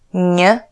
Pronounciation
Newton, news